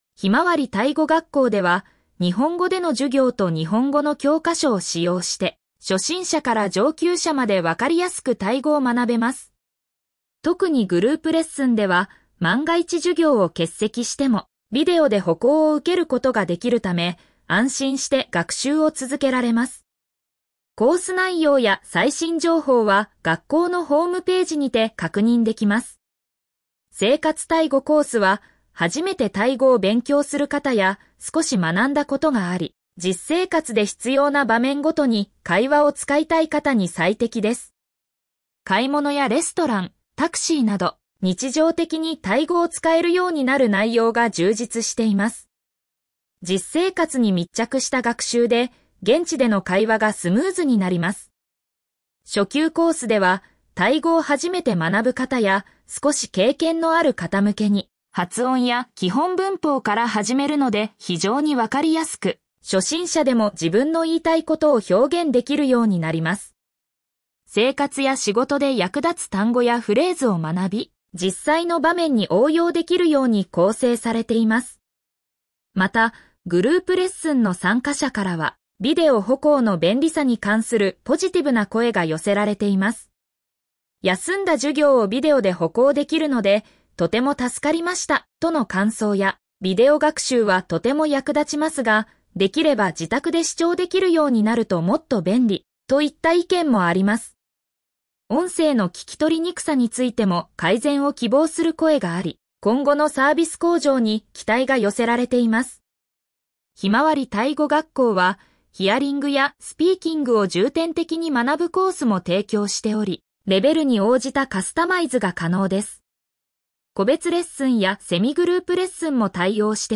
ペルプ バンコク・メディプレックスビルディング リンク先 「ひまわりタイ語学校」授業風景 グループ 「ひまわりタイ語学校」授業風景 個人 読み上げ ひまわりタイ語学校では、日本語での授業と日本語の教科書を使用して、初心者から上級者までわかりやすくタイ語を学べます。